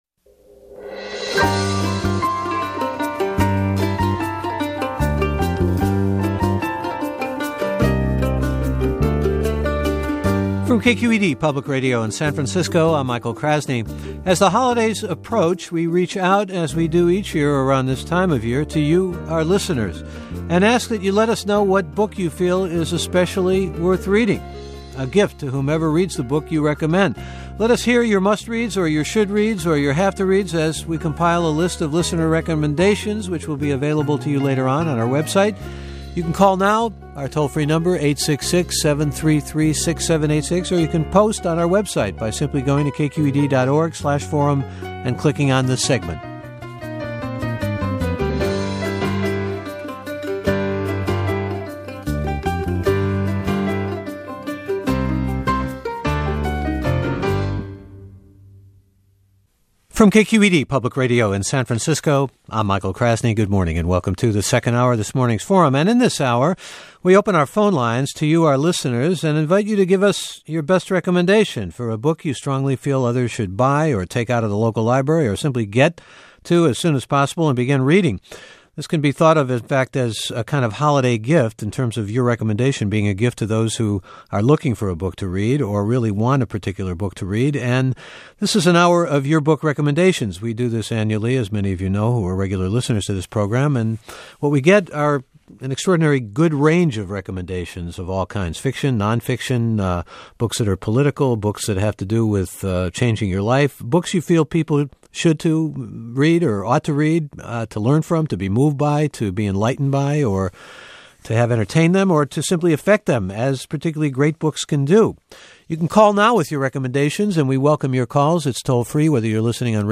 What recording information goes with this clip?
What was the best book you read this year? We open the phone lines to ask listeners about their recent and all-time favorites.